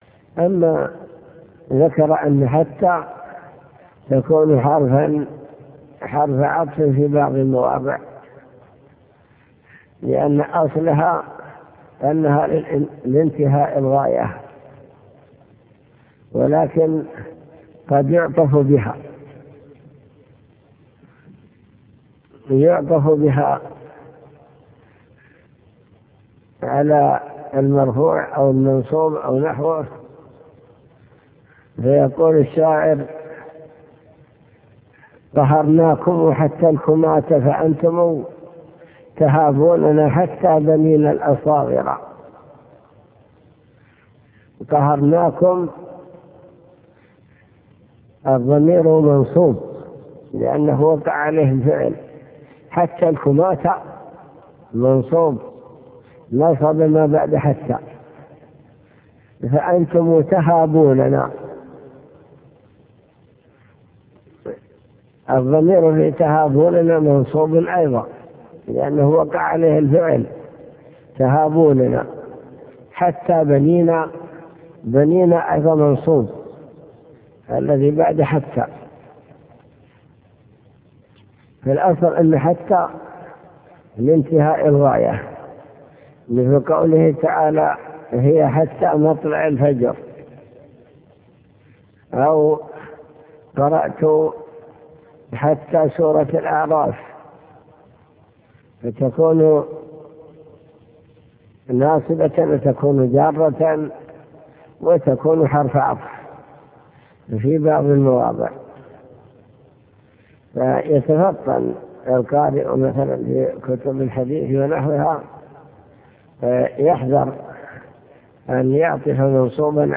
المكتبة الصوتية  تسجيلات - كتب  شرح كتاب الآجرومية العطف وأقسامه حروف العطف